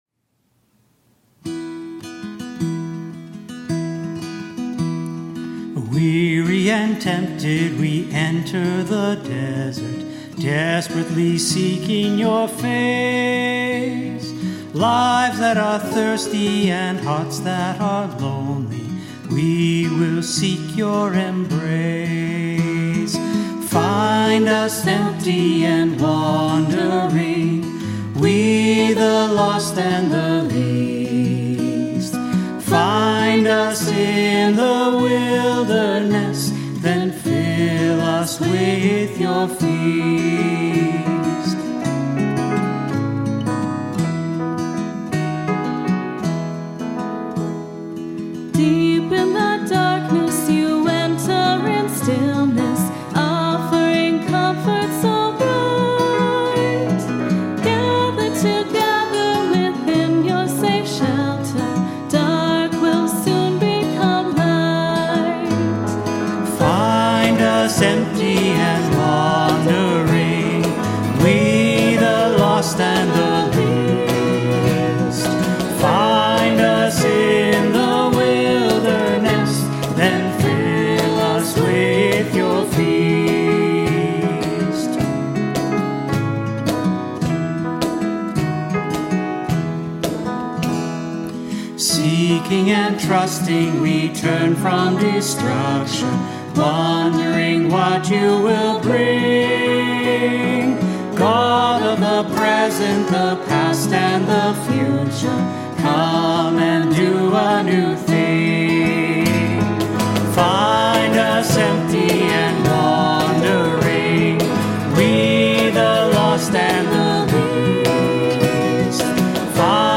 HYMN: Phil Campbell-Enns ©
fill-us-with-your-feast-voices-together.mp3